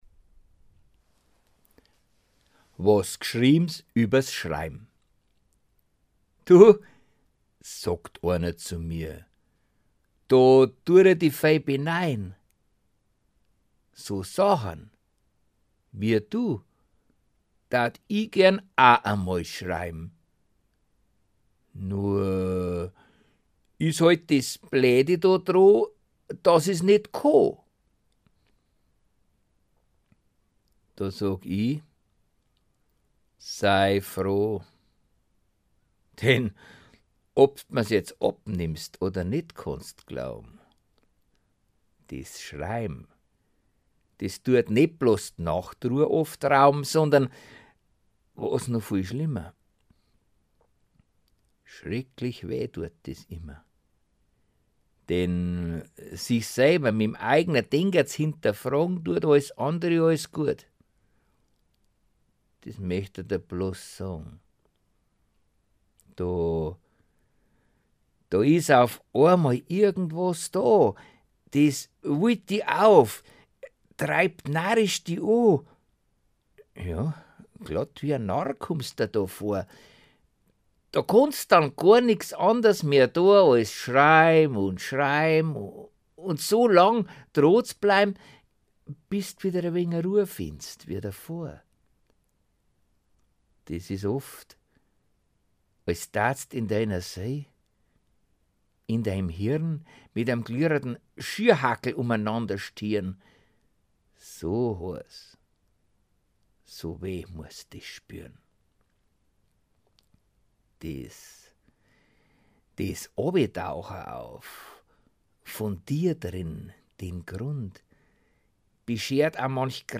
Rezitation:
gesprochen am 18. Oktober 2016